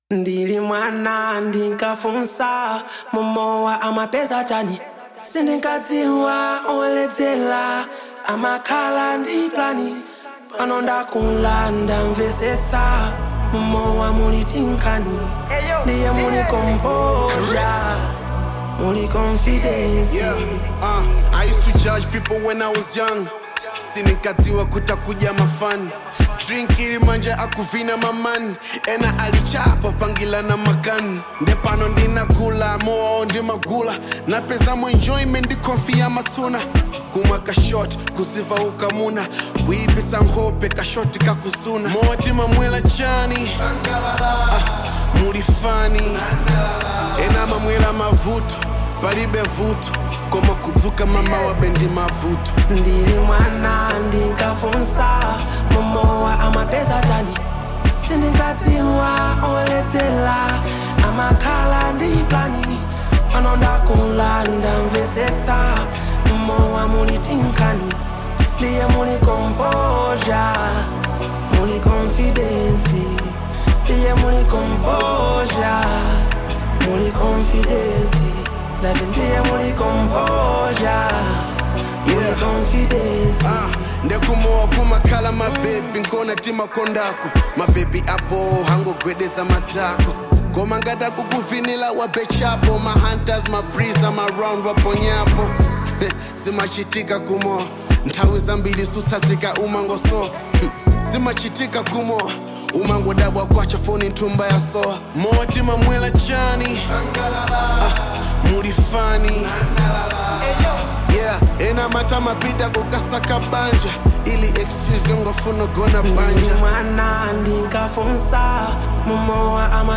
Genre : Hiphop/Rap